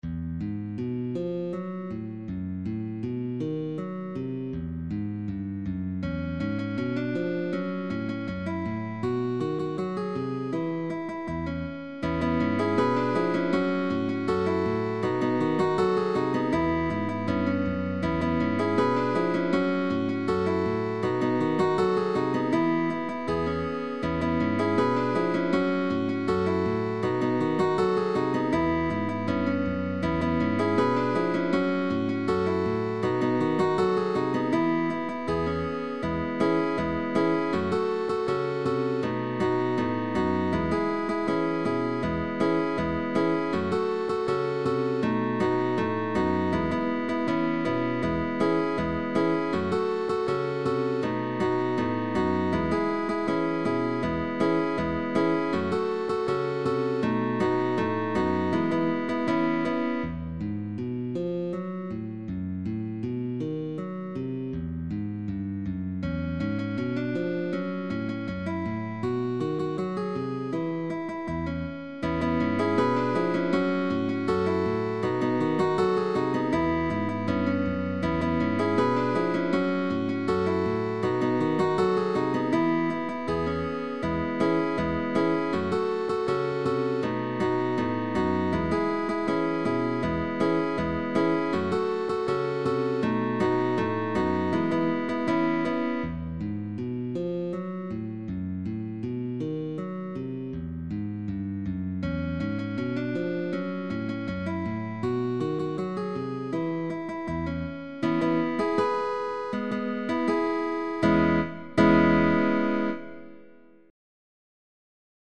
CUARTETO DE GUITARRAS
Partitura para cuarteto de guitarras con bajo opcional.
Pulgar: Melodía en cuerdas graves. Acordes de dos y tres notas.